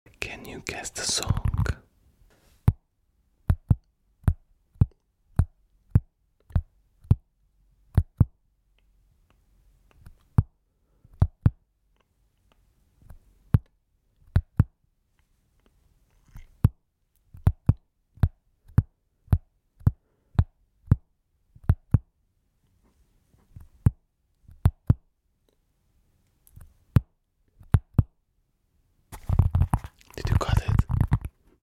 ASMR | Guess the Song by Tapping